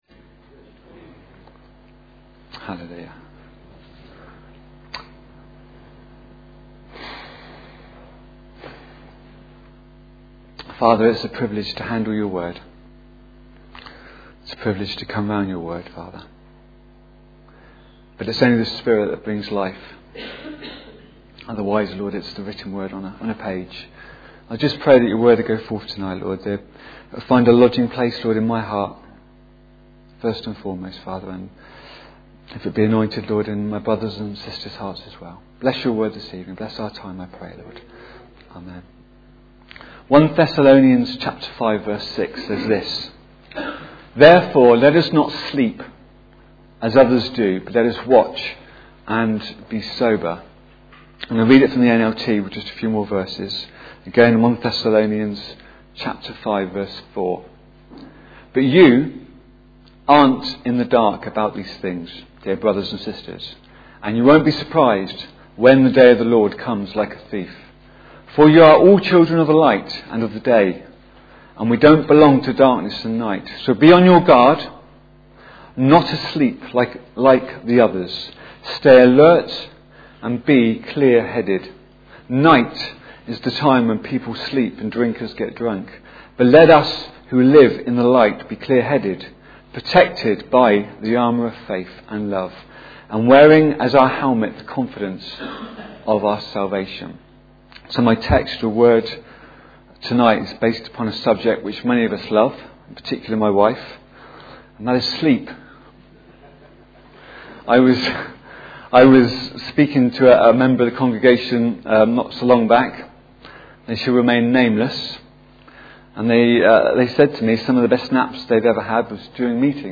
Sermons Archive - Page 31 of 31 - Calvary Pentecostal Church